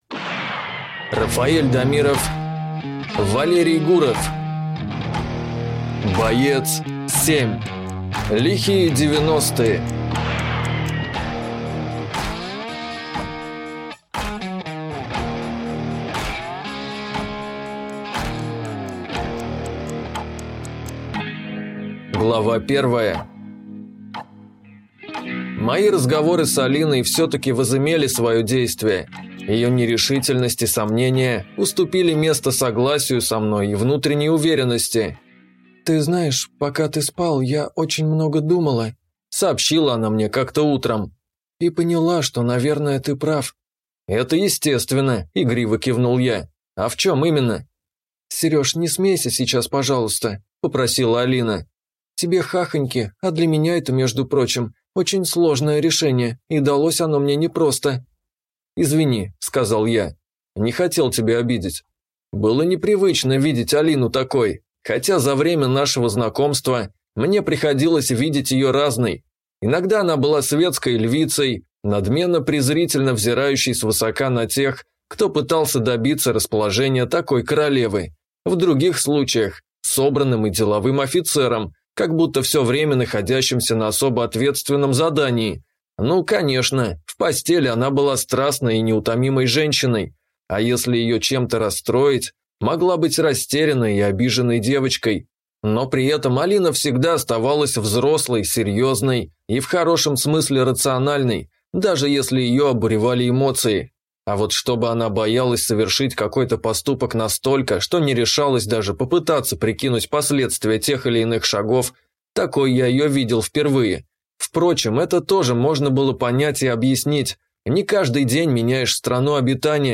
Филер уголовного сыска (слушать аудиокнигу бесплатно) - автор Денис Бурмистров